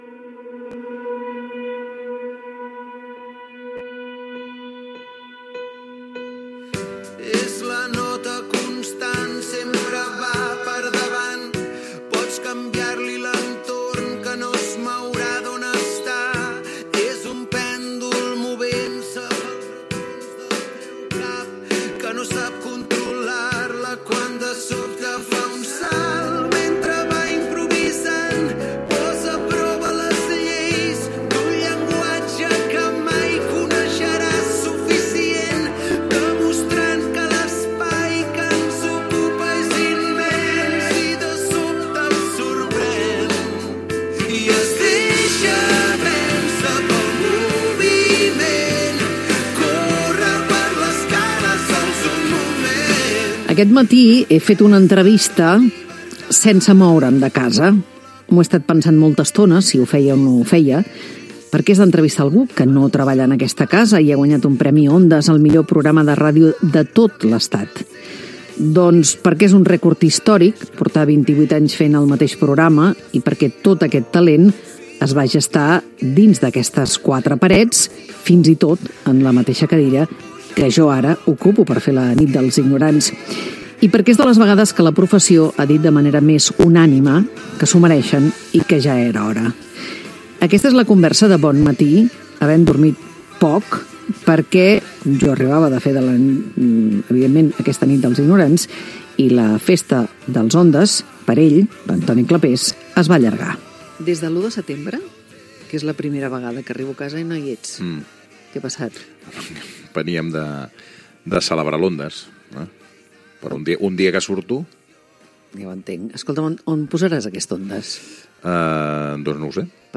Entrevista a Toni Clapés, l'endemà de la festa dels Premis Ondas, director i presentador del "Versió RAC1".